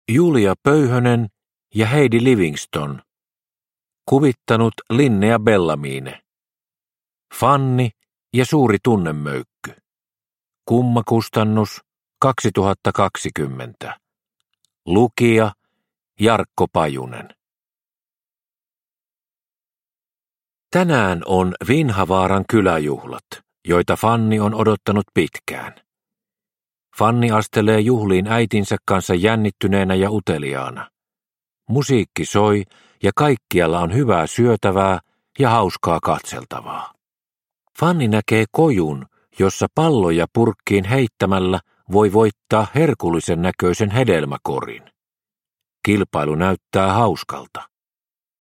Fanni ja suuri tunnemöykky – Ljudbok – Laddas ner